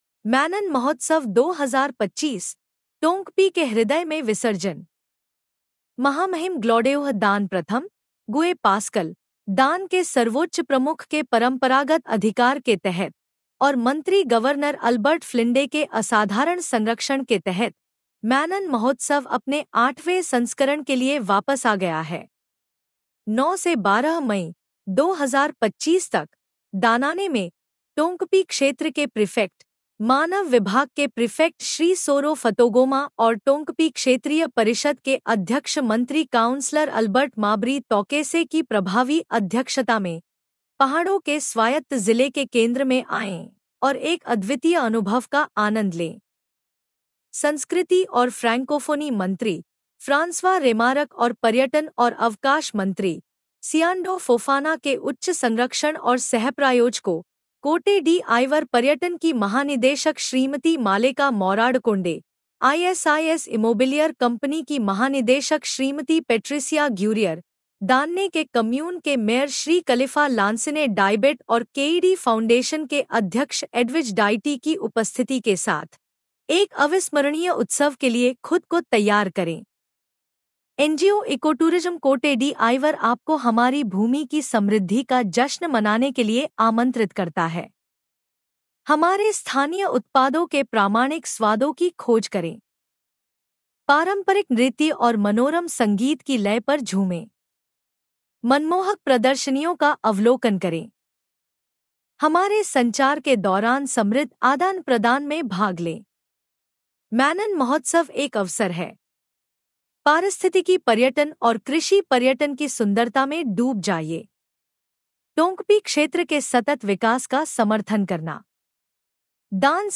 “मैनन फेस्टिवल” 2025 के शुभारंभ पर
7 मार्च, 2025 को, दानाने टाउन हॉल, मैनन फेस्टिवल के 8वें संस्करण के दूसरे आधिकारिक शुभारंभ की लय में गूंज उठा।